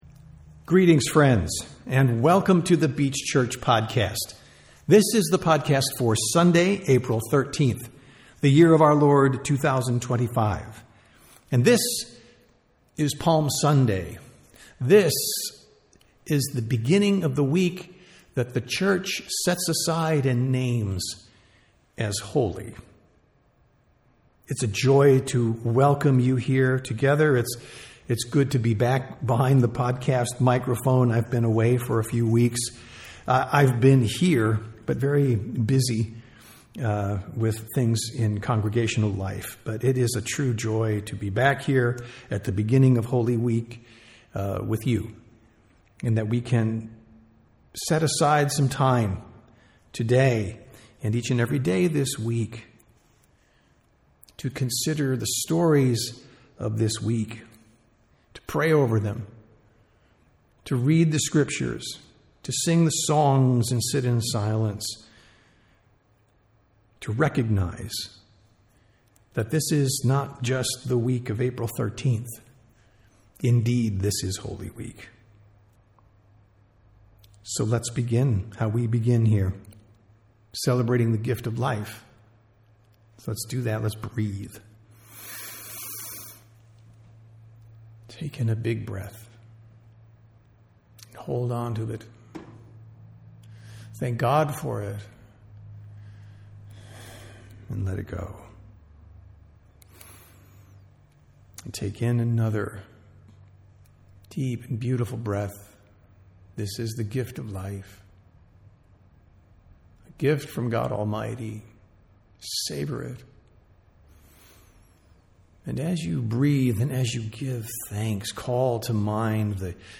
Sermons | The Beach Church
Sunday Worship - April 13, 2025